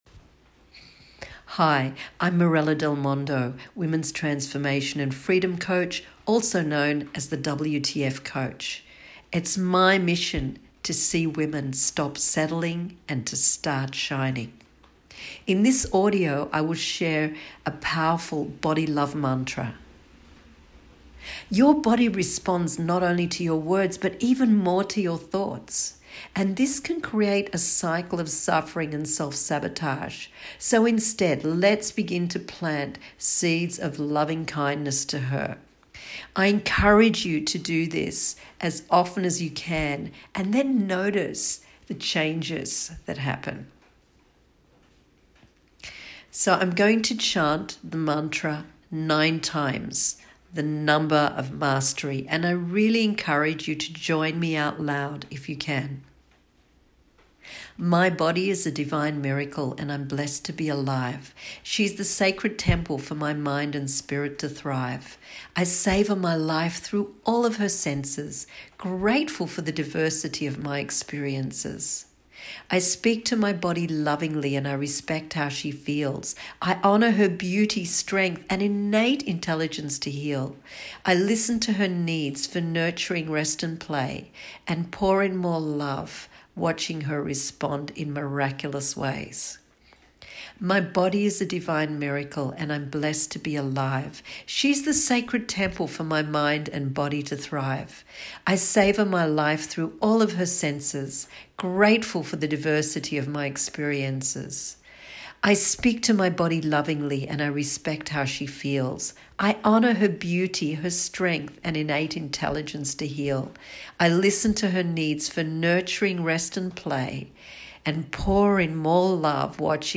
I recorded these short mantras for myself to keep me on track!
Body Love Mantra
Body+love+Mantra.m4a